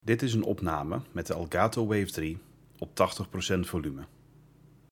De volumetesten zijn gedaan met een afstand naar de microfoon van 10 cm en zonder verbeteringen ingeschakeld in Open Broadcaster Software.
Elgato Wave:3 - 80% volume
Microfoonopname-Elgato-Wave3-80-volume.mp3